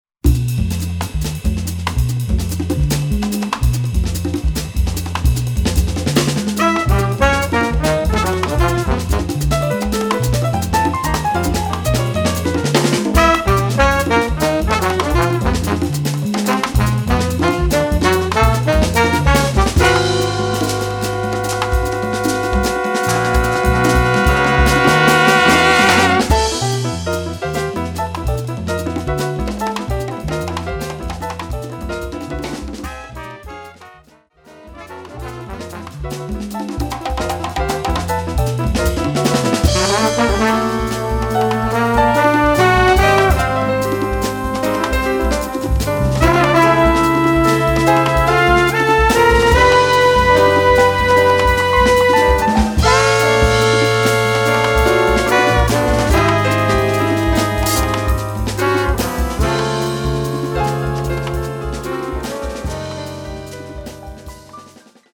Category: combo (septet)
Style: rumba
Solos: open